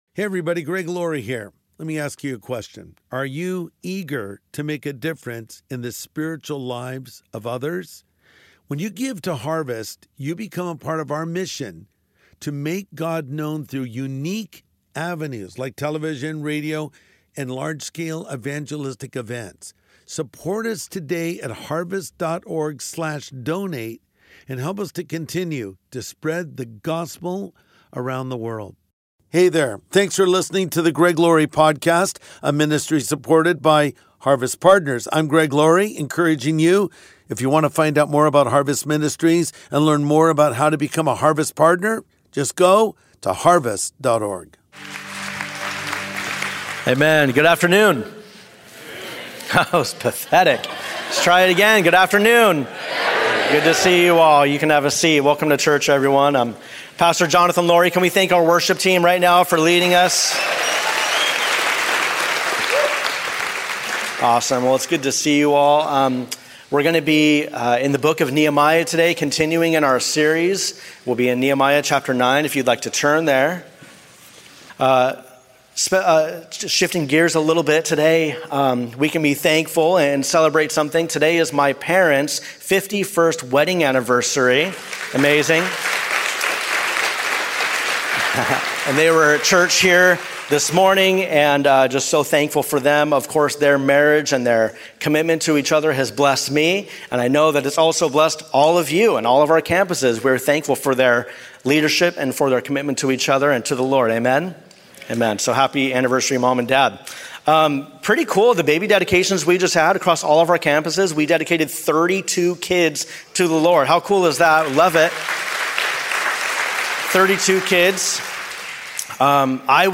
Rebuilding More Than Walls | Sunday Message